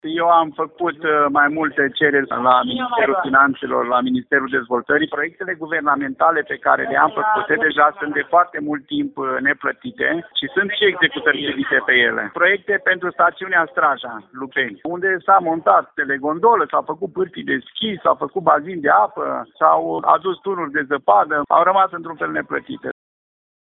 Și muncipiul Lupeni a primit fonduri de la guvern pentru diverse lucrări realizate și neachitate care erau în procedura de executare silită, spune primarul Cornel Răzmeliță.
primar-lupeni.mp3